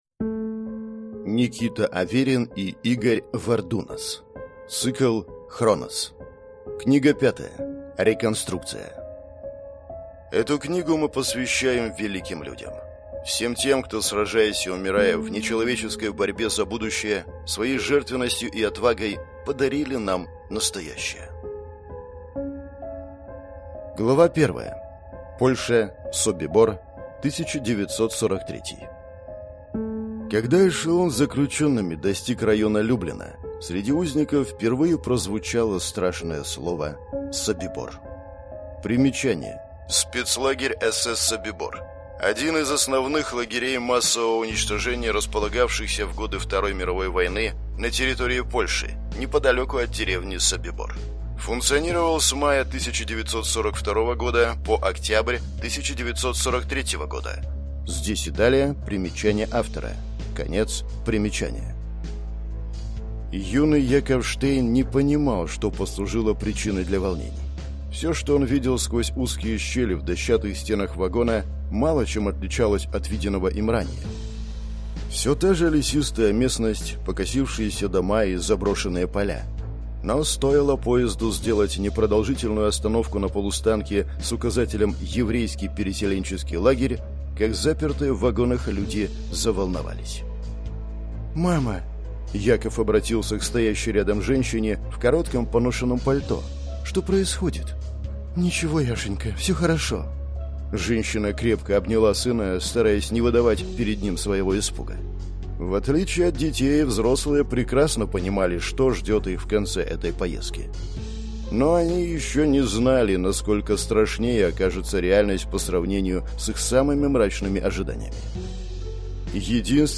Аудиокнига Хронос. Книга 5. Реконструкция | Библиотека аудиокниг